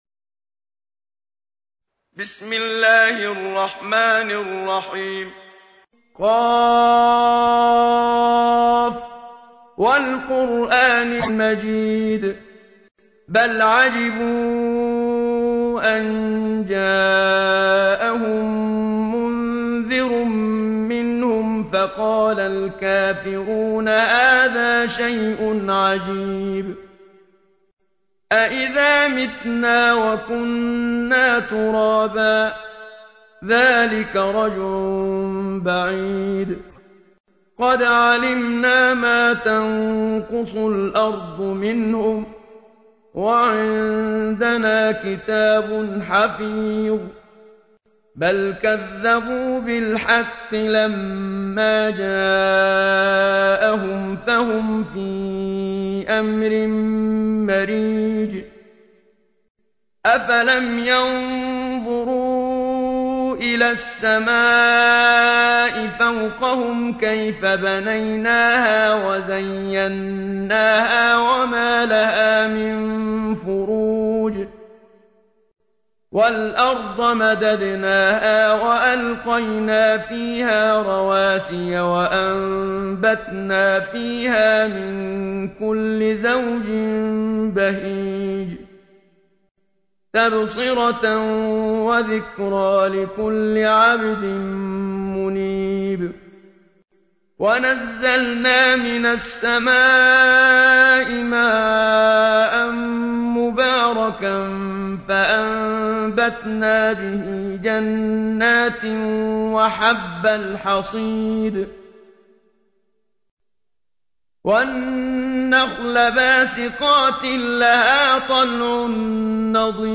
تحميل : 50. سورة ق / القارئ محمد صديق المنشاوي / القرآن الكريم / موقع يا حسين